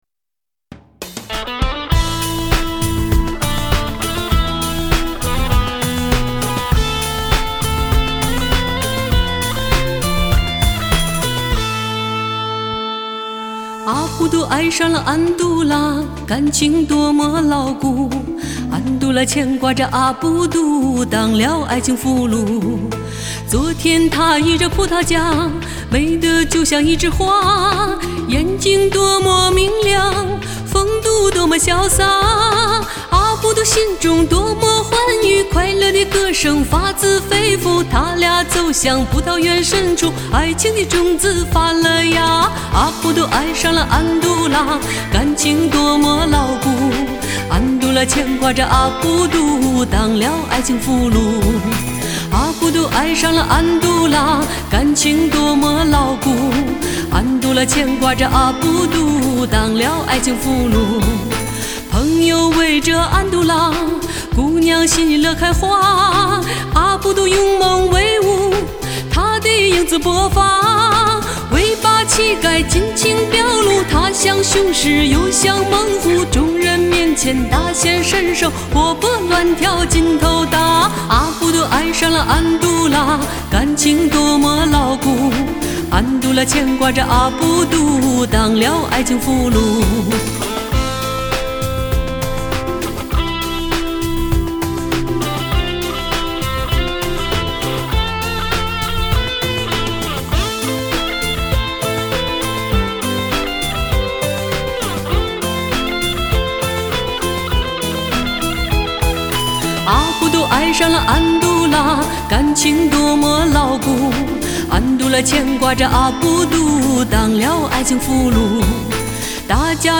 黎巴嫩民歌